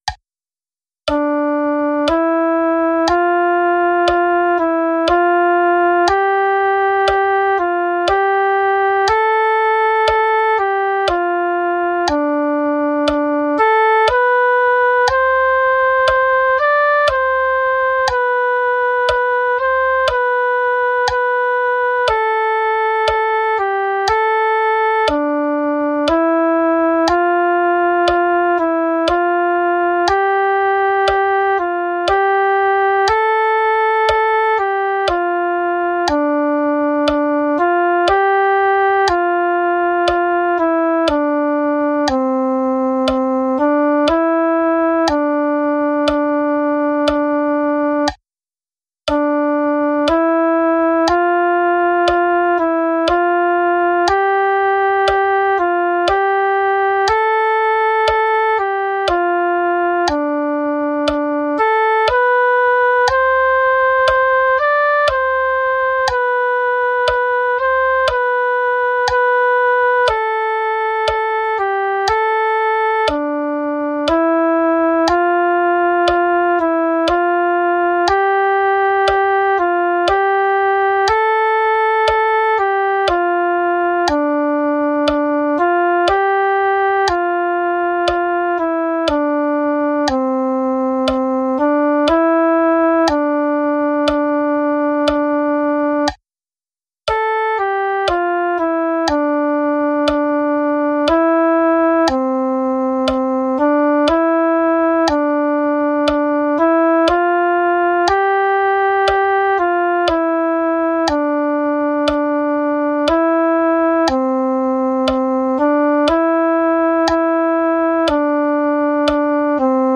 Arreglo para flauta dulce, partitura en pdf y audio en mp3.